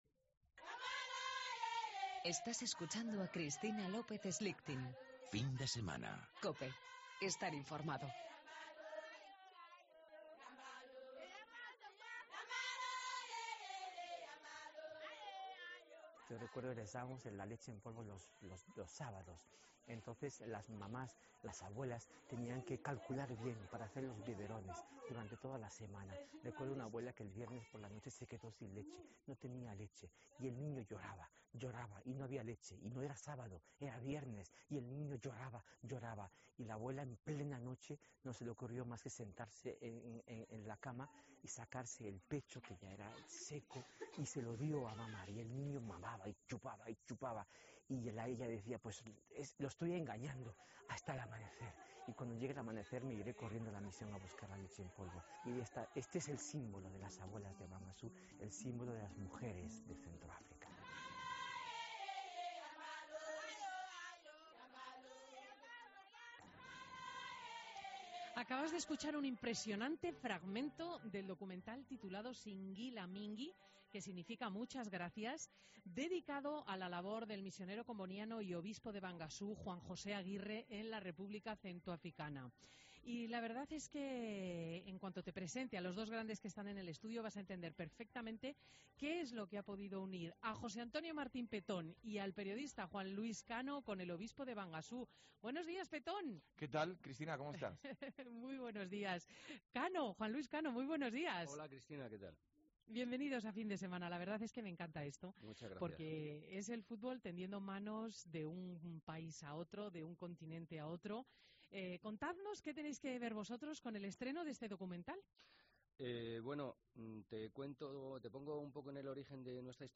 Entrevista a Petón y Juan Luis Cano, miembros de la peña atlética 'Los 50'